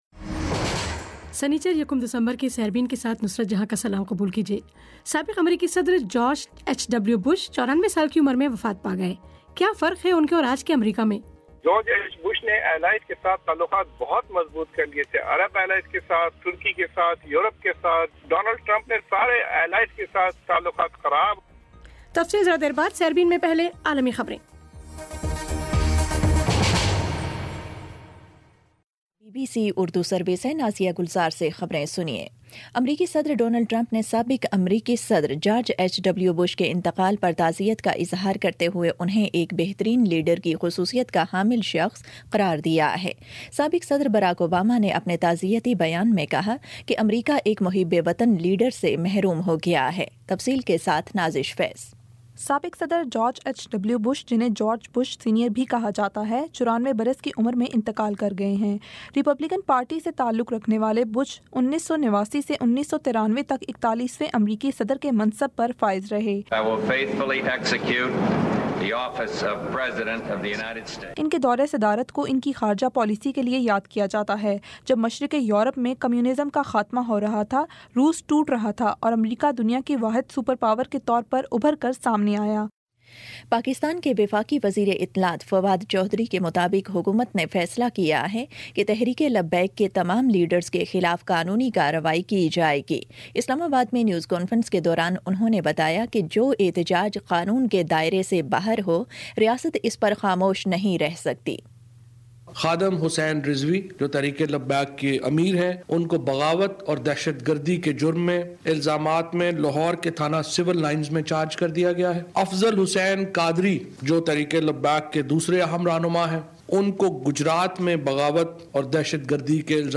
بی بی سی اردو کا فلیگ شپ ریڈیو پروگرام روزانہ پاکستانی وقت کے مطابق رات آٹھ بجے پیش کیا جاتا ہے جسے آپ ہماری ویب سائٹ، اپنے موبائل فون، ڈیسک ٹاپ، ٹیبلٹ، لیپ ٹاپ اور اب فیس بُک پر سن سکتے ہیں